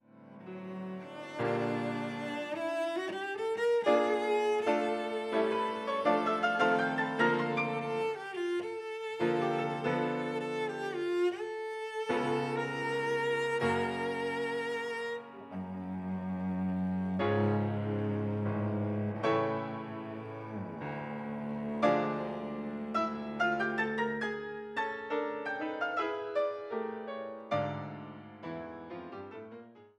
Cello und Klavier
Kammermusik aus Böhmen